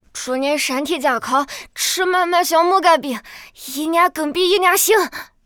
c01_6卖艺小孩B_1.wav